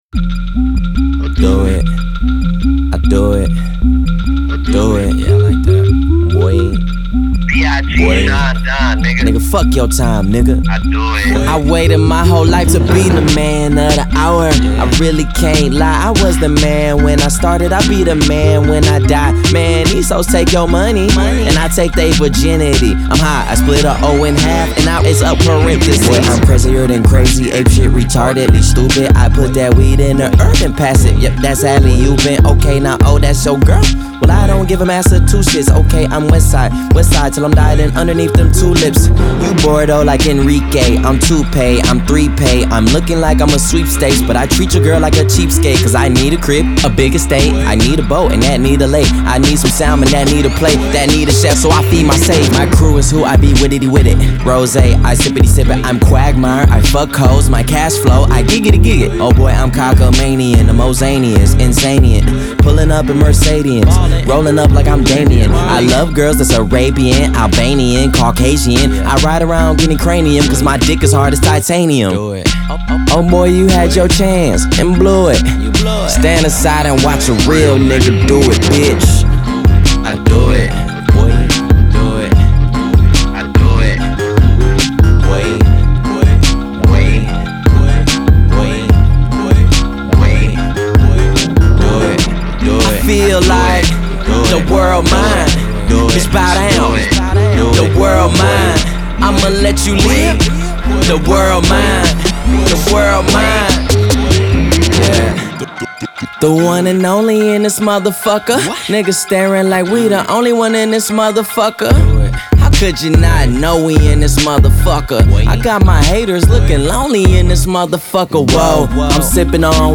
Genre : Rap, Hip Hop